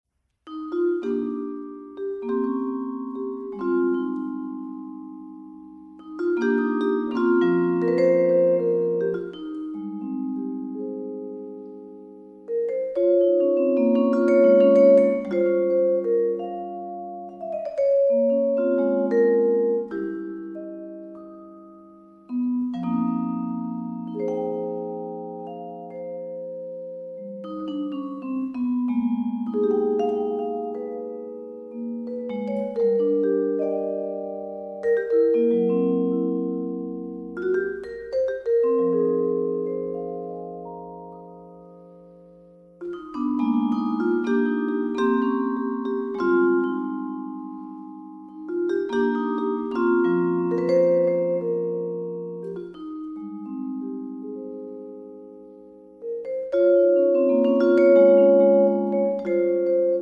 vibraphone